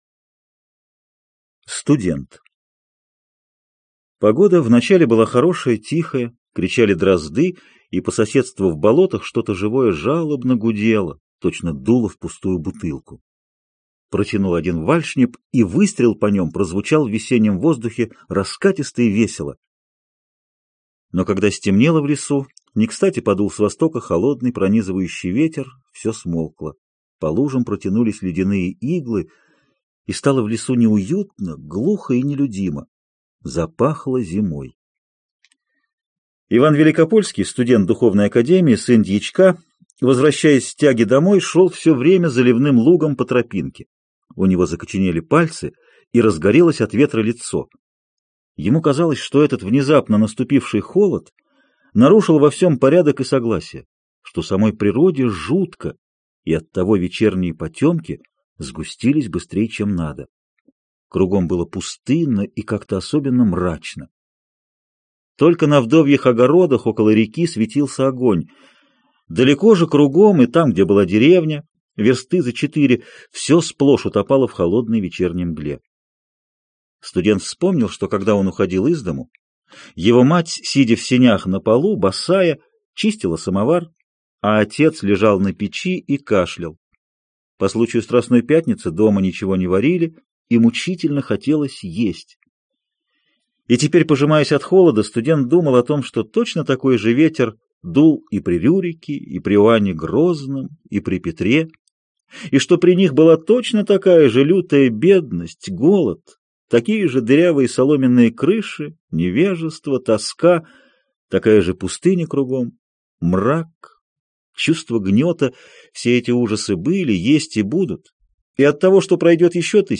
Аудиокнига Хрестоматия. часть 2 | Библиотека аудиокниг
Aудиокнига Хрестоматия. часть 2 Автор Антон Чехов